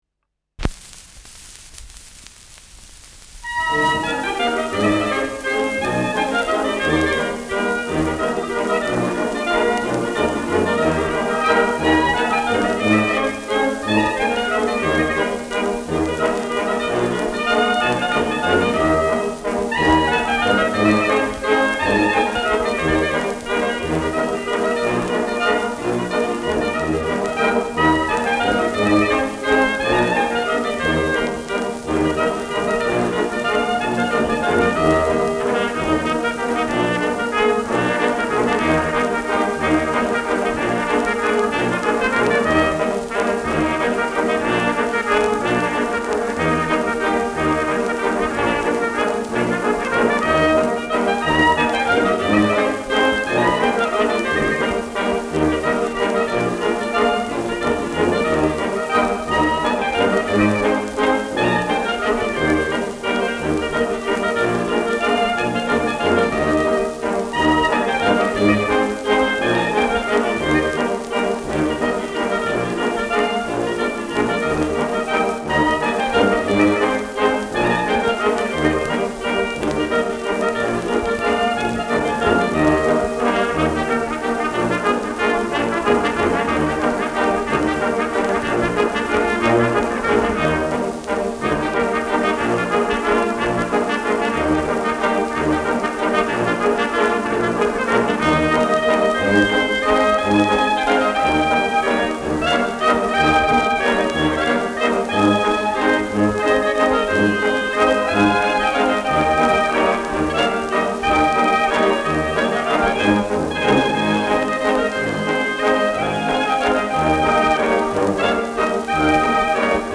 Schellackplatte
Leichtes Grundrauschen : Gelegentlich leichtes bis stärkeres Knacken
[München] (Aufnahmeort)